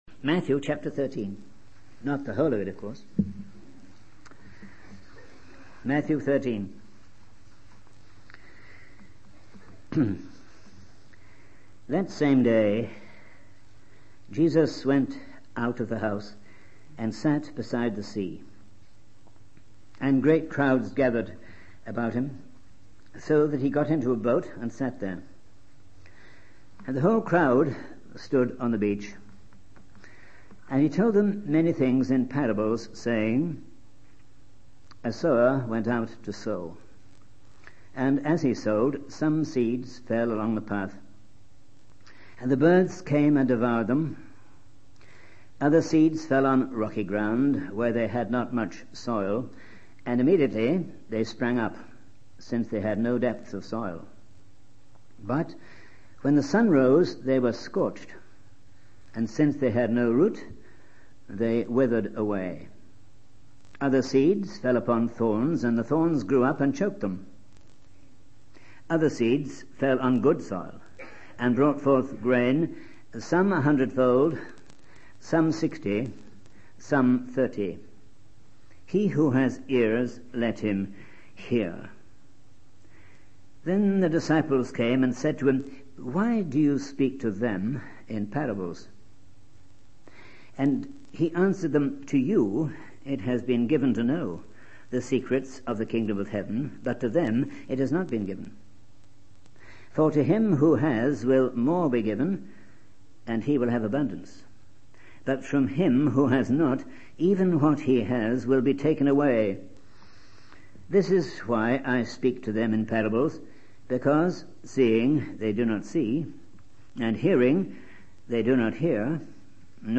In this sermon, the preacher focuses on the parables of Jesus found in Matthew 13. He highlights the first four parables, which depict conflict, failure, and the presence of evil in the world.